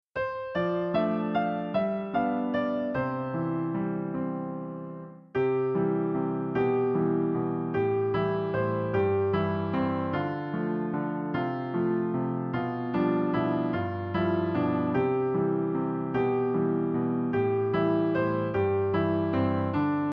Sheet Music — Piano Solo Download
Downloadable Instrumental Track